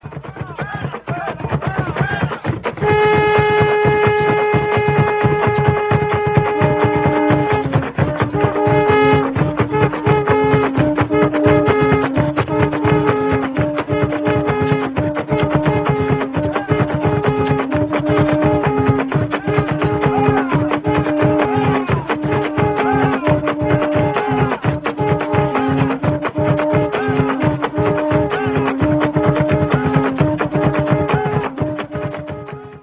Trompeta natural
Aerófono, de soplo, trompeta.
El soplo se emite con los labios vibrantes, para producir un sonido fuerte y penetrante.
Ensamble: Tres guaruras, tambores cumaco, laures y voces
Característica: Durante las festividades de San Juan Bautista en la costa central del país, la guarura goza de popularidad y se toca tanto al ritmo de los tambores como solas en grupos de dos o tres.
Grupo Cultural: Afrovenezolano
Procedencia, año: Guayabal, Estado Miranda, Venezuela, 1979